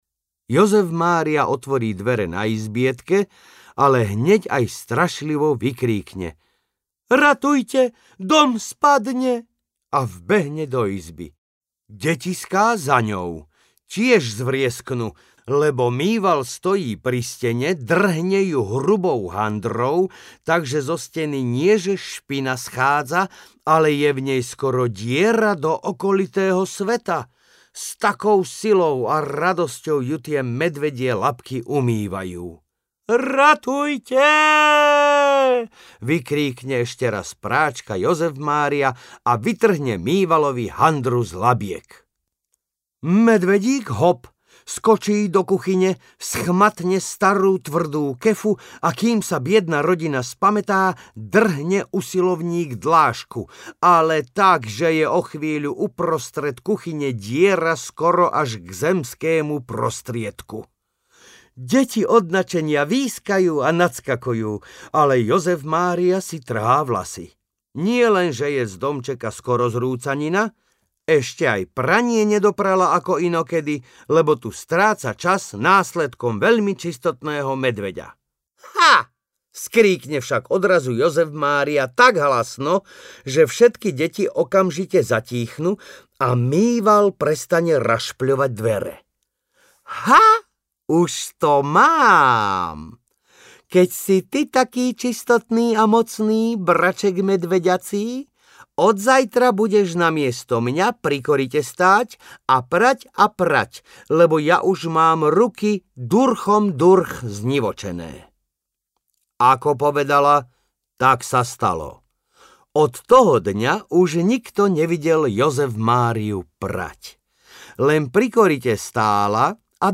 Audio knihaBrumlíčkove rozprávky
Ukázka z knihy